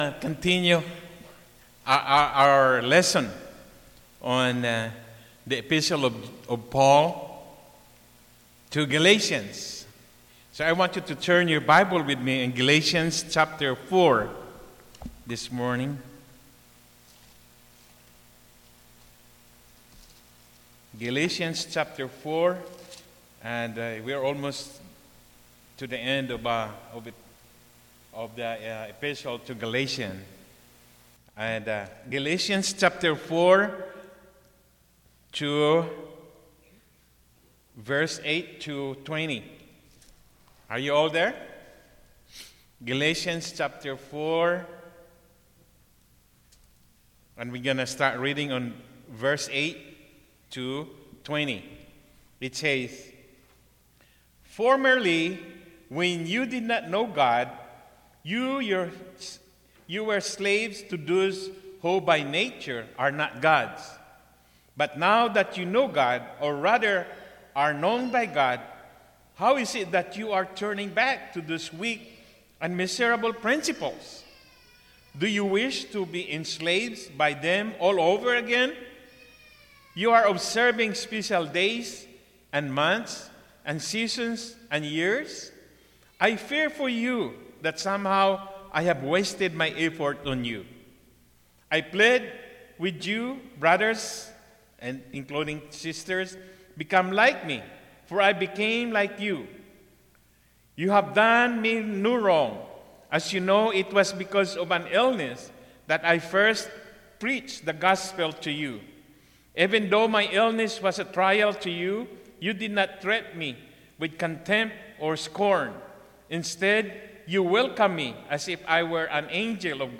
Marks of The Great Christian Church Message from International Christian Faith Church .